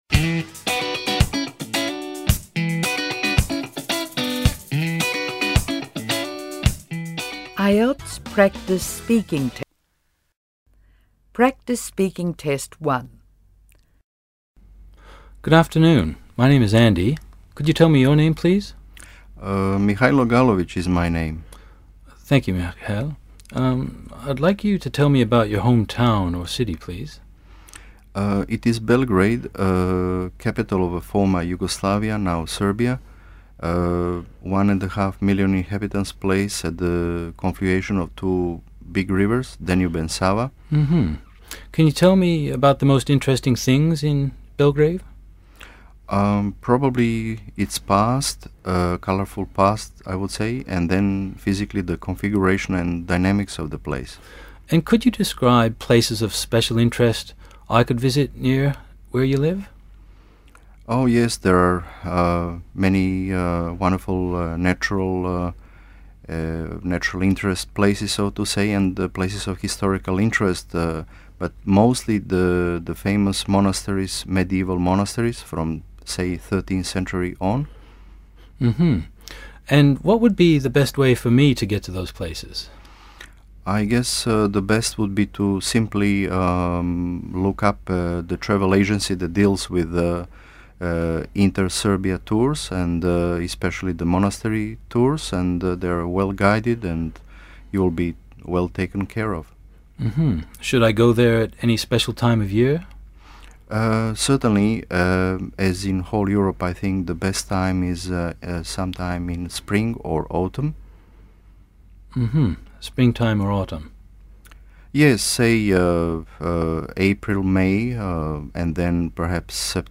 PRACTICE SPEAKING TEST 1